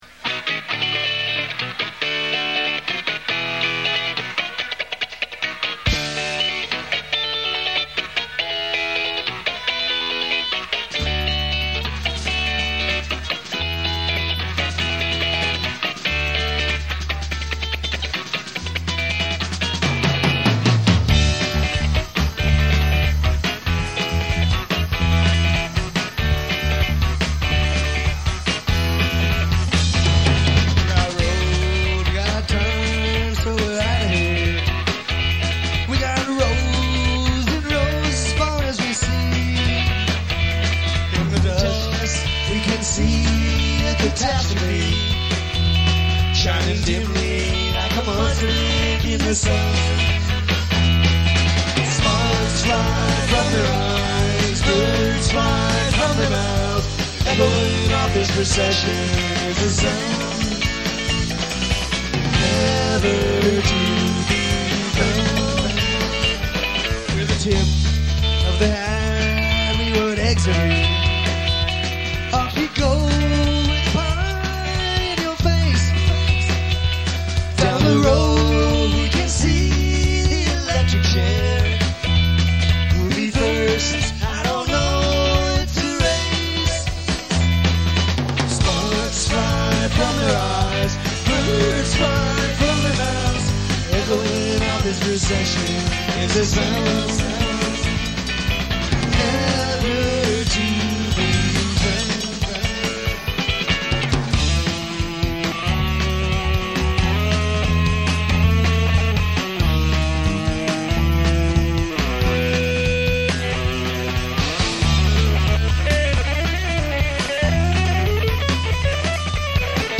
Slims, San Francisco, CA